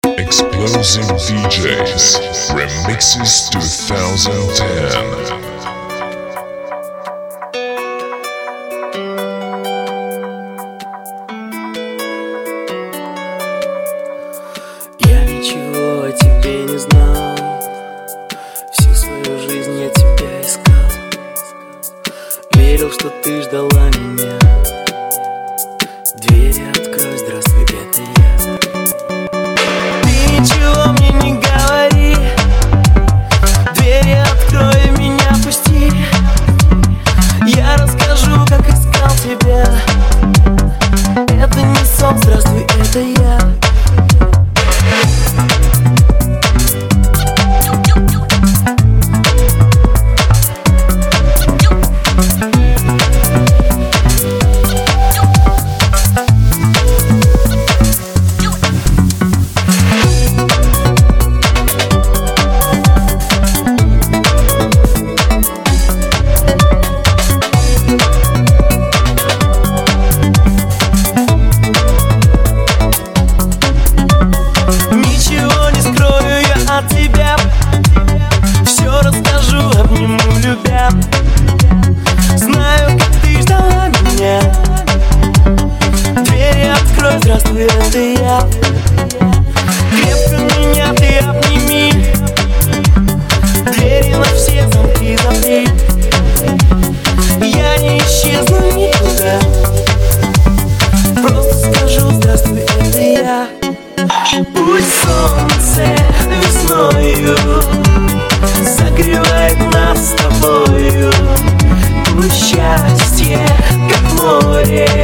Категория: Club - Mix